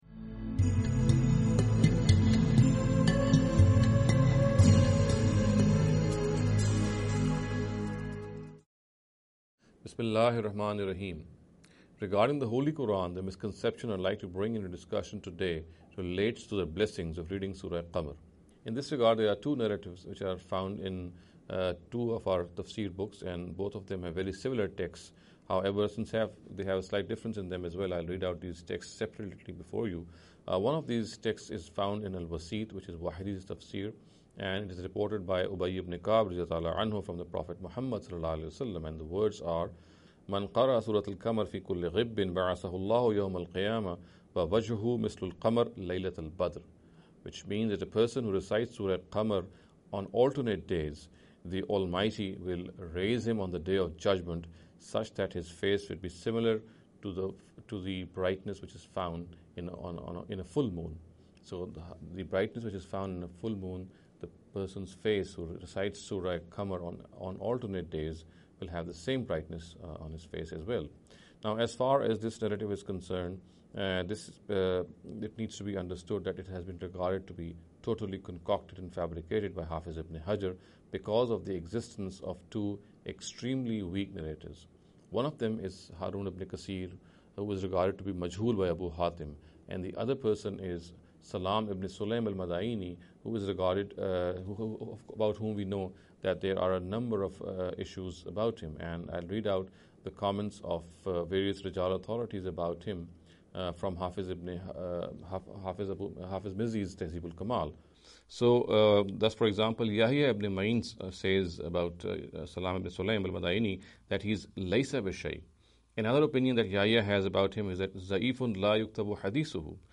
In every lecture he will be dealing with a question in a short and very concise manner.